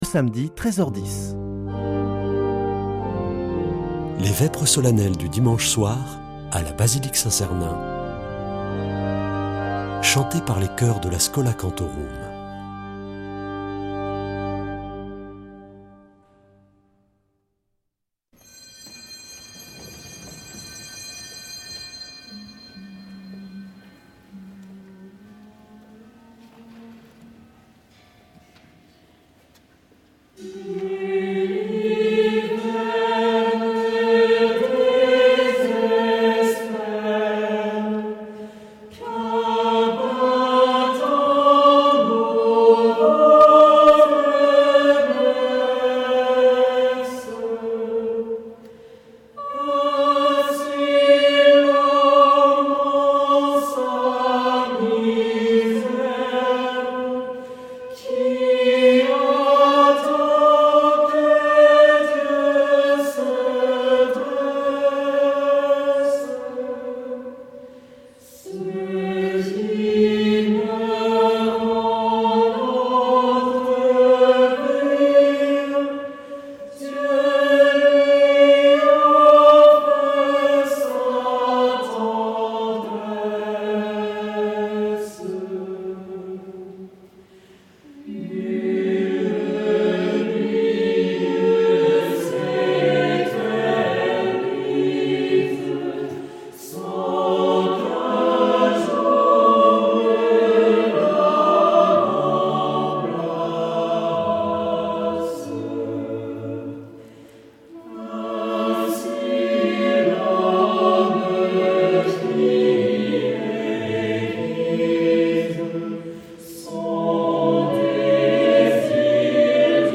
Vêpres de Saint Sernin du 18 févr.
Une émission présentée par Schola Saint Sernin Chanteurs